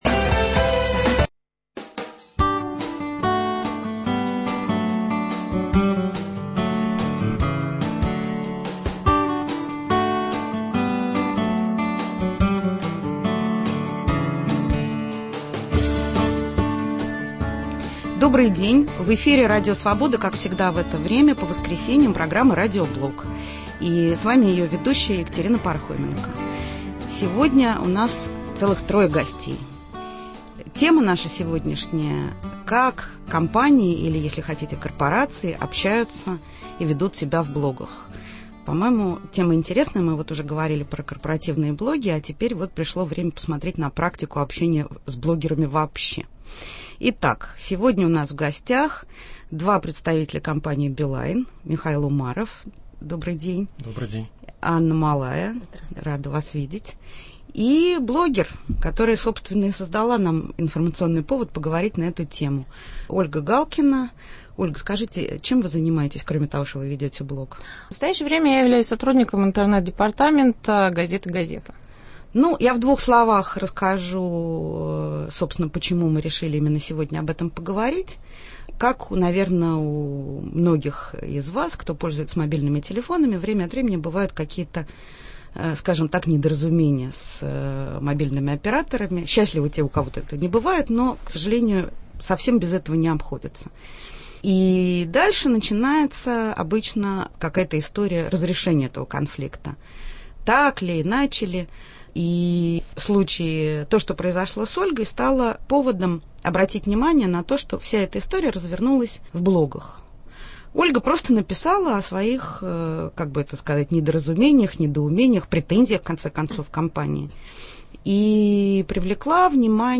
О коммуникациях в блогах между корпорацией и ее клиентом. Как использовать новые информационные каналы для таких коммуникаций. Гости в студии: